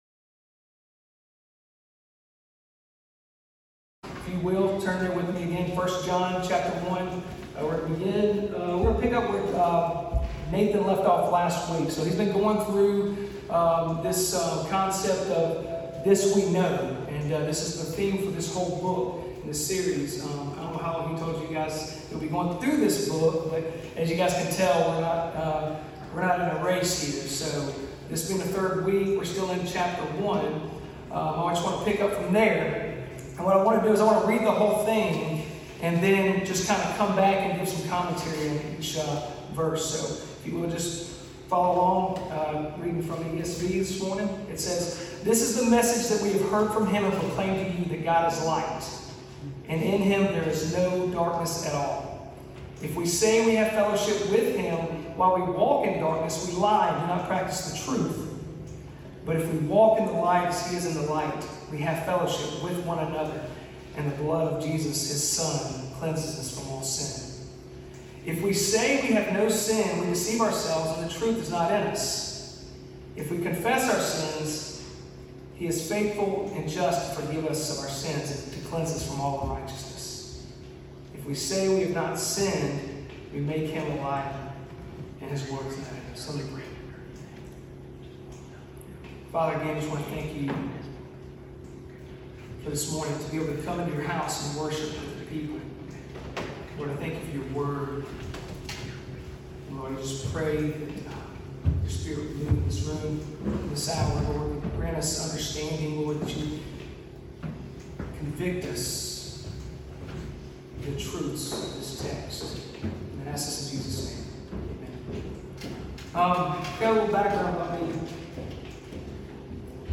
A Sermon Series Through First John 1 John 1:5-10 Guest Speaker